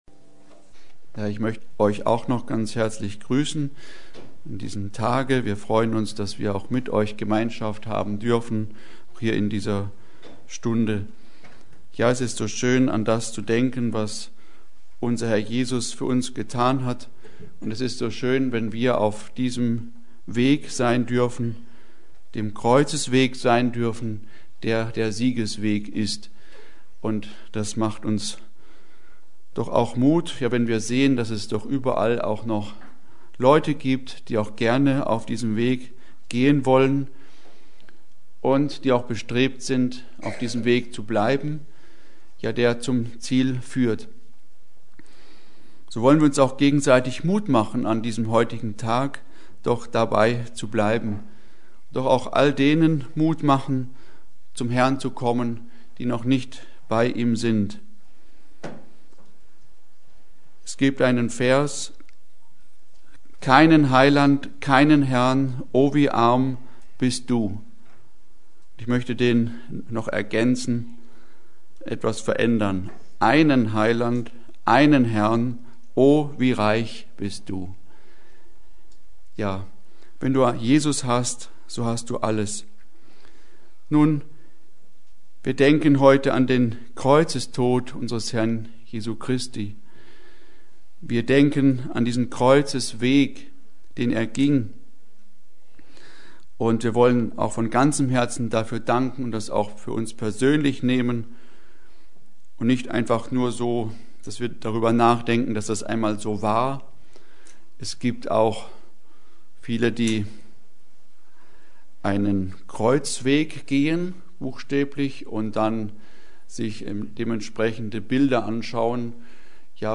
Predigt: Kreuzesweg – Siegesweg
Serie: Karfreitagstreffen 2026 Passage: Johannes 19,28-37